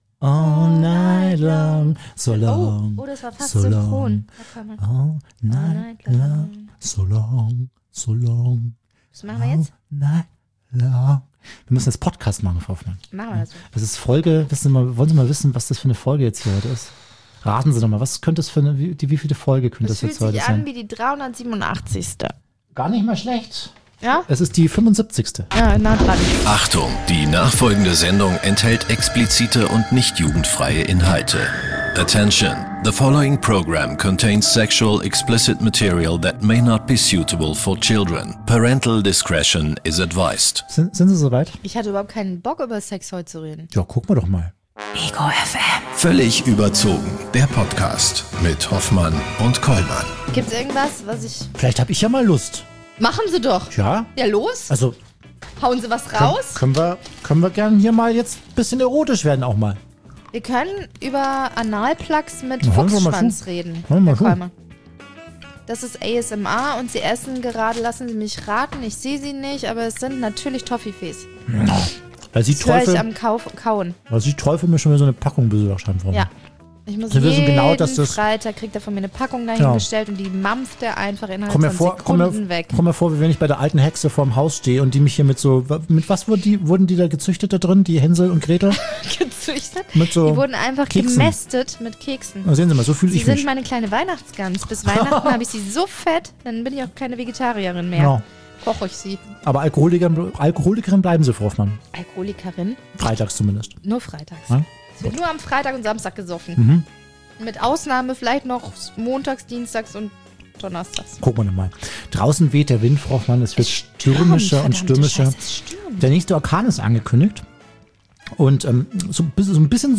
Gast: Sven Plöger (Meterologe & Autor)